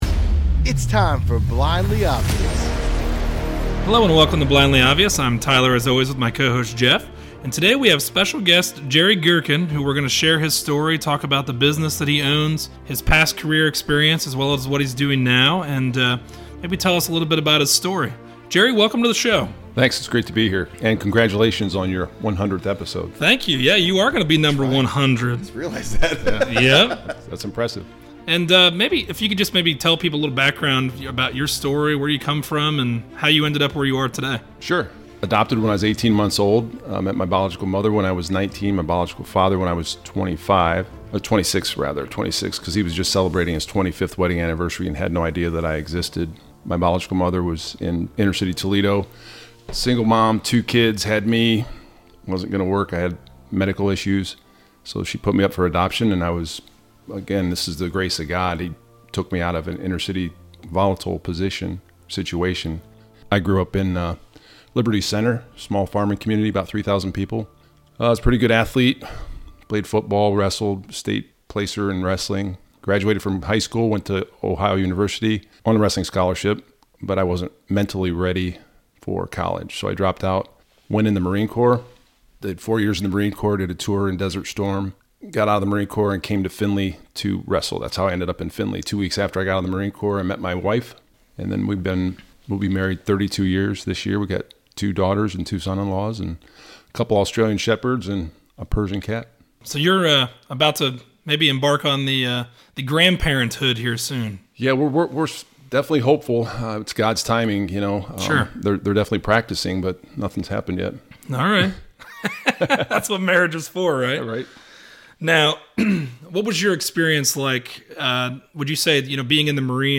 Subscribe An interview